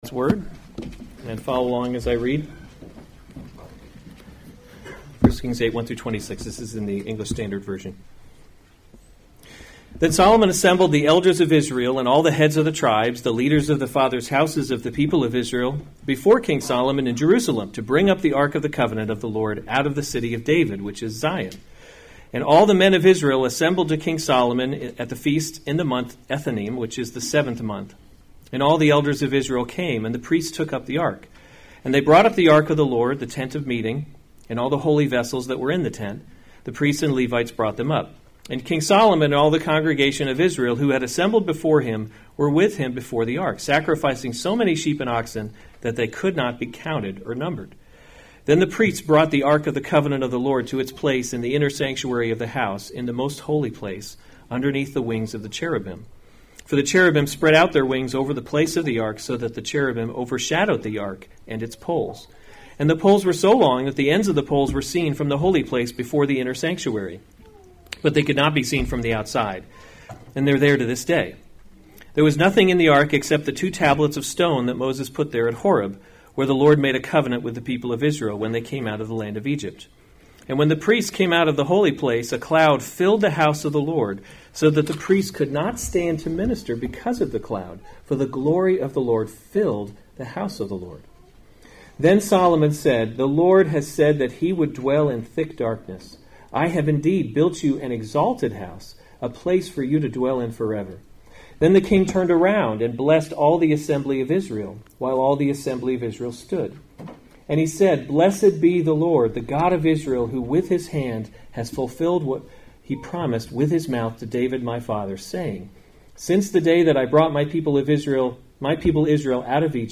November 3, 2018 1 Kings – Leadership in a Broken World series Weekly Sunday Service Save/Download this sermon 1 Kings 8:1-26 Other sermons from 1 Kings The Ark Brought into […]